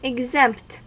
Ääntäminen
IPA : /ɪɡ.ˈzɛmpt/ US : IPA : [ɪɡ.ˈzɛmpt]